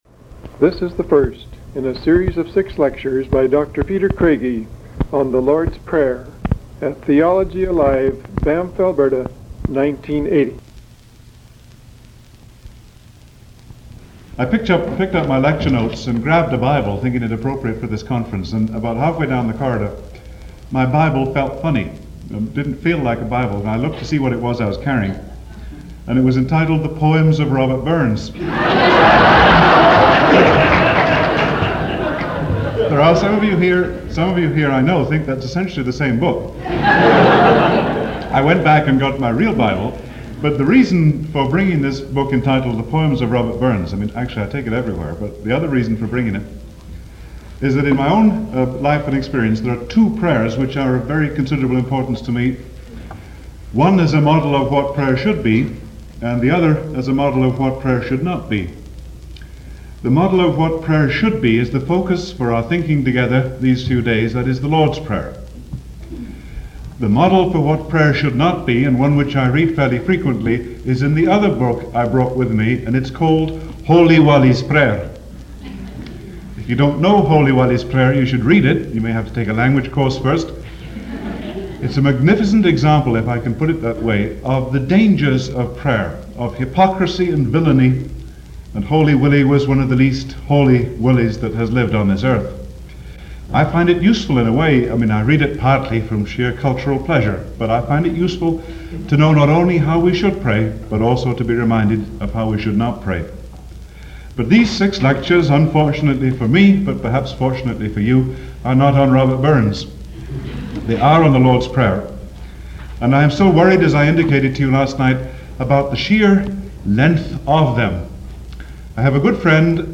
Lecture 1